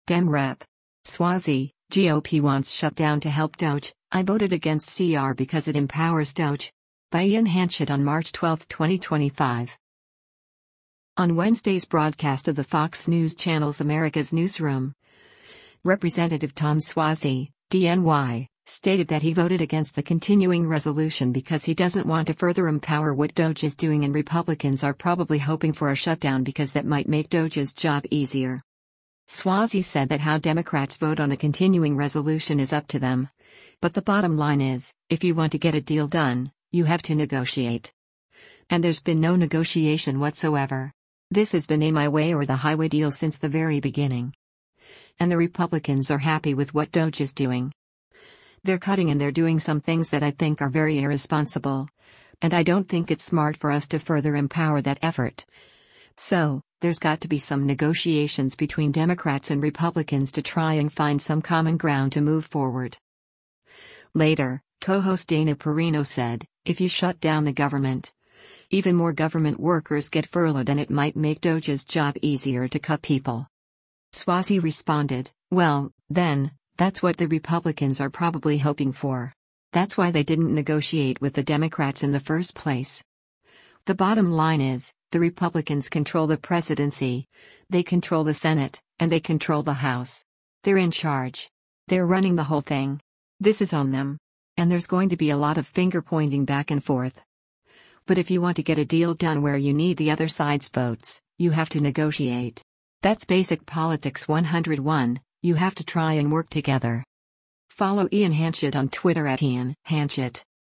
On Wednesday’s broadcast of the Fox News Channel’s “America’s Newsroom,” Rep. Tom Suozzi (D-NY) stated that he voted against the continuing resolution because he doesn’t want “to further empower” what DOGE is doing and “Republicans are probably hoping for” a shutdown because that might make DOGE’s job easier.